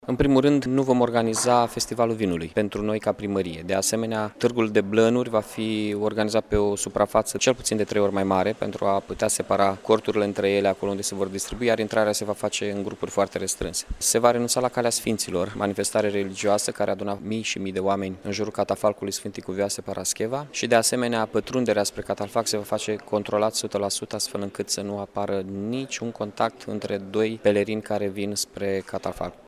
Sărbătorile Iaşului din acest an vor respecta măsurile de distanţare socială impuse de hotărârile adoptate la Bucureşti, a anunţat, astăzi, primarul Mihai Chirica.